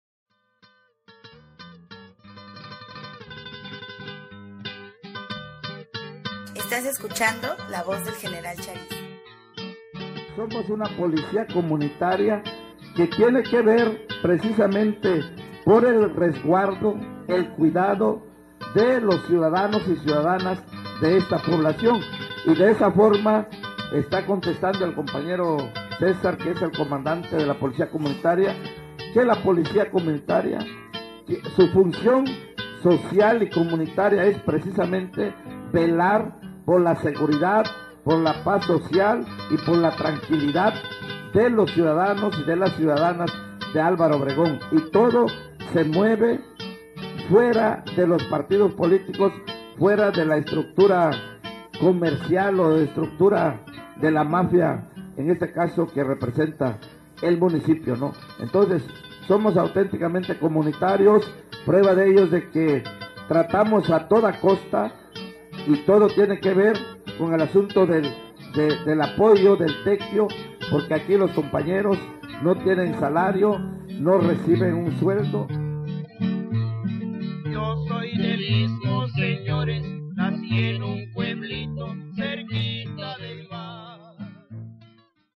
A continuación te invitamos a escuchar algunos audios de pobladores de esta comunidad y ver algunas imágenes de este territorio
Capsúla de la radio la Voz del General Charis